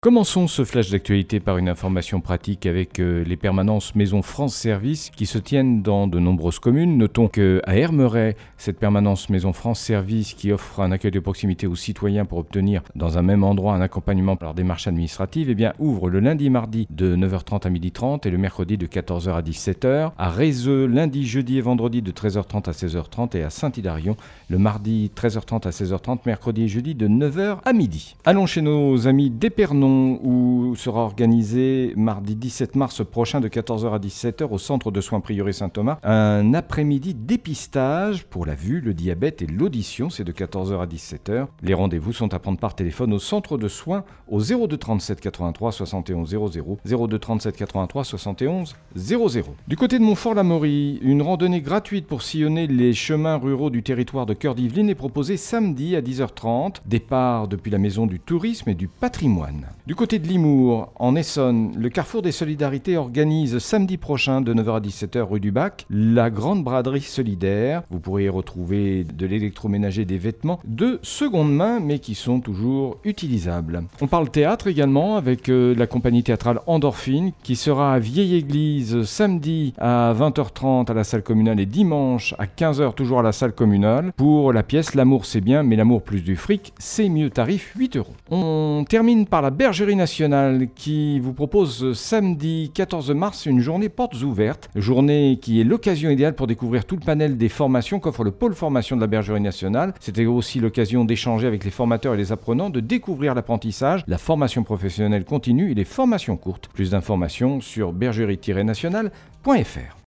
10.03-flash-local-matin.mp3